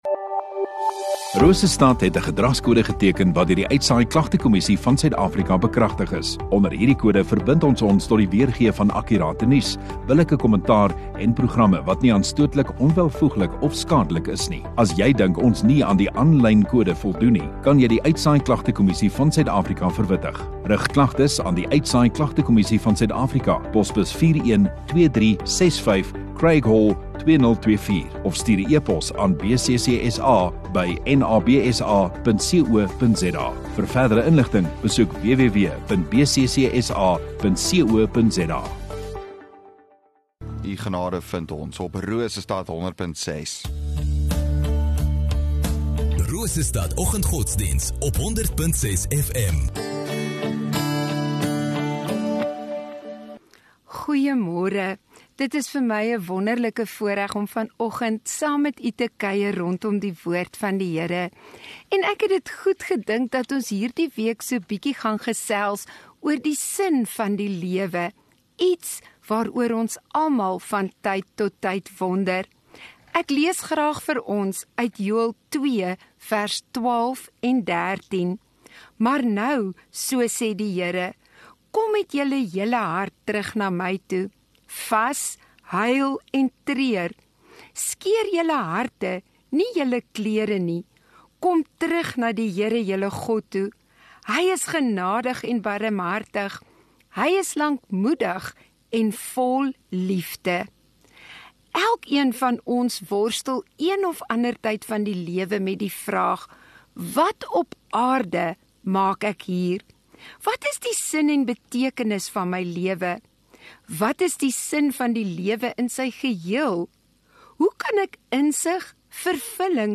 9 Mar Maandag Oggenddiens